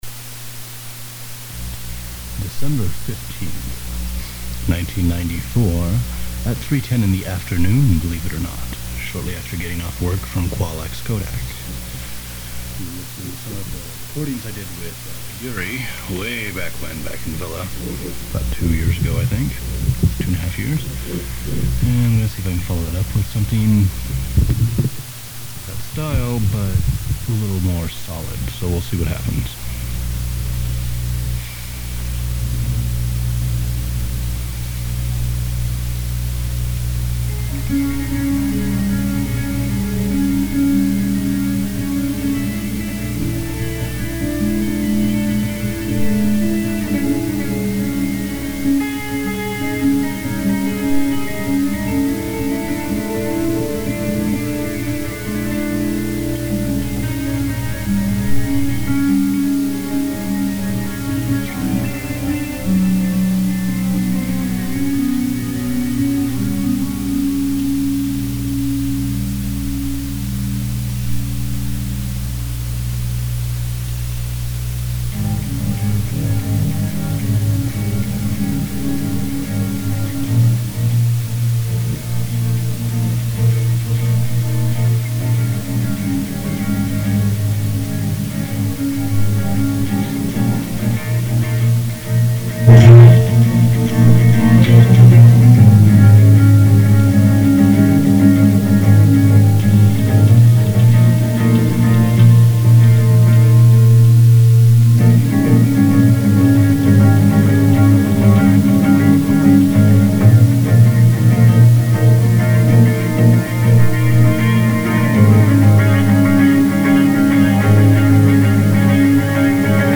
Meanderings Yurism Guitar Meanderings (Based On Yurisms)